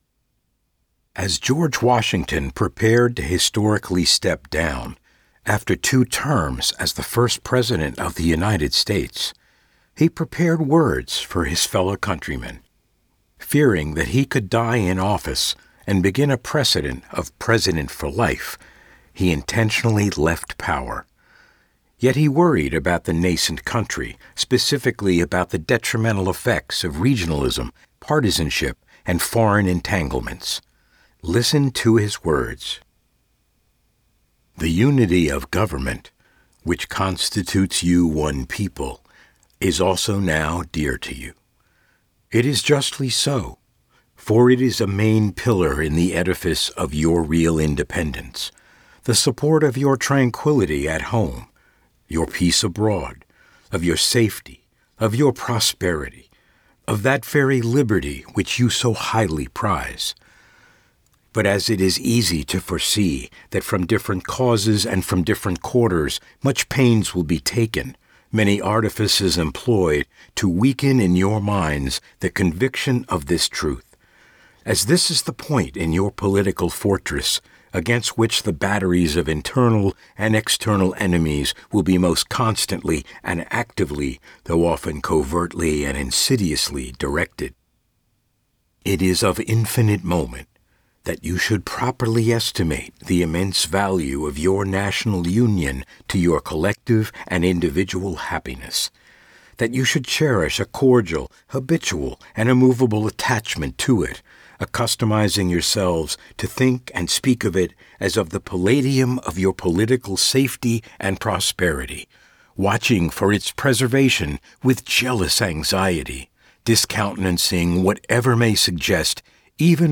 George Washington narration
Middle Aged
Senior
washington narration.mp3